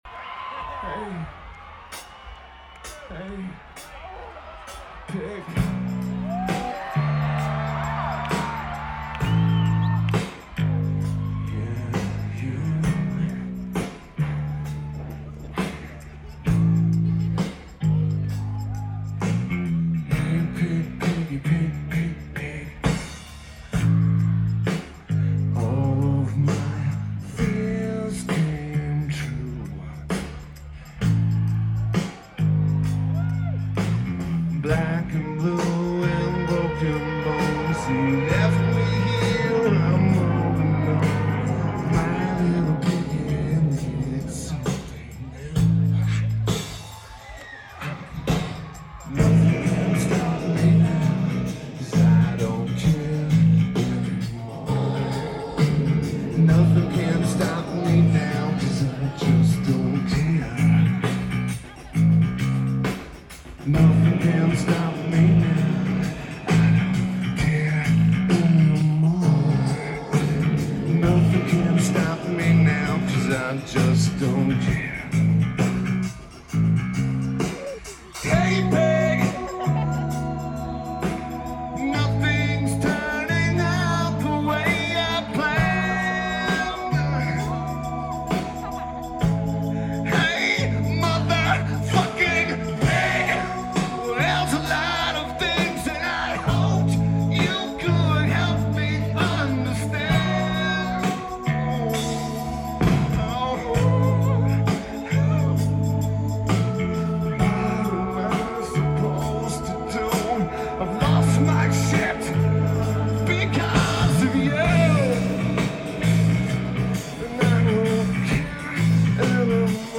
Coachella Music & Arts Festival
Lineage: Audio - AUD (DPA 4060 + Sony PCM-M1)
Very clear recording and just amazing.